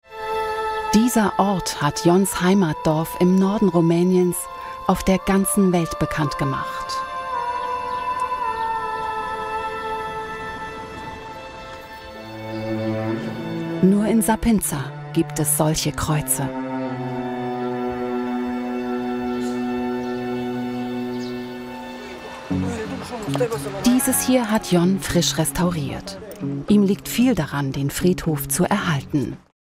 Erfahrene Profisprecherin
ARTE TV - Doku Voice
2-ARTE-I-Doku-22Besonderer-Friedhof22.mp3